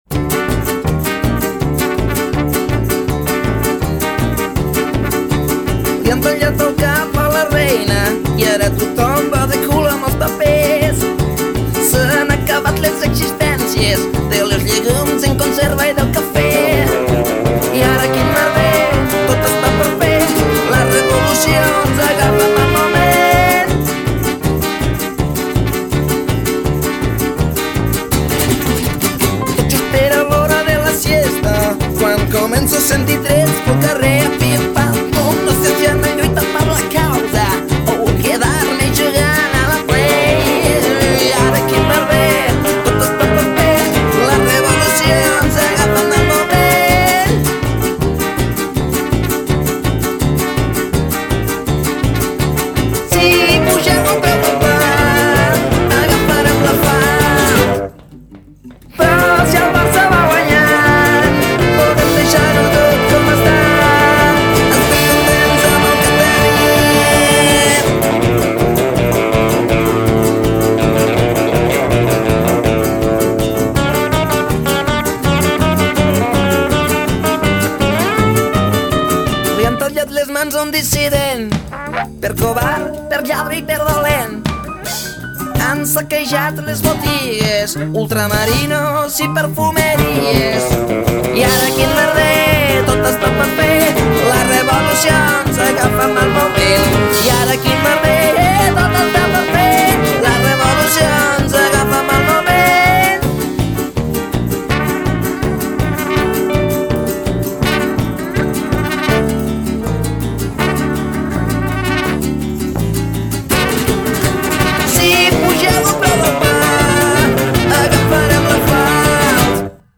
Jazz o rockabilly? Pop o rock? Rumba o folk?